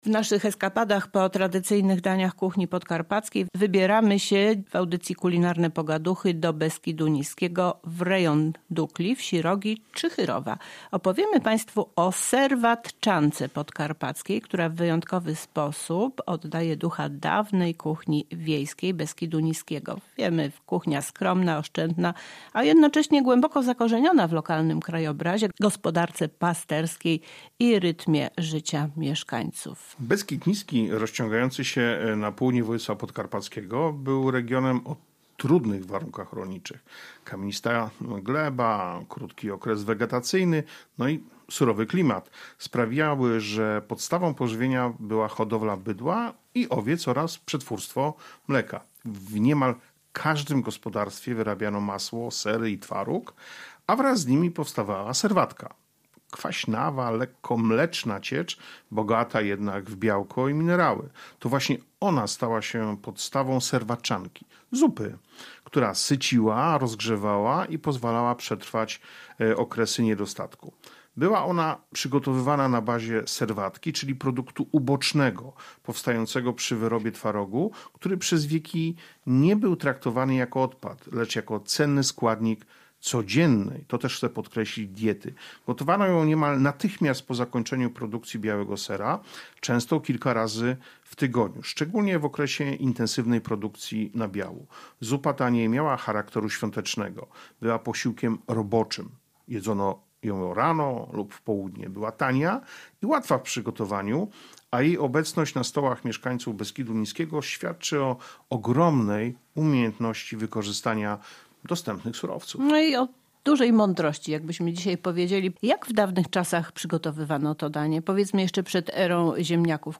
Więcej o serwatczance w rozmowie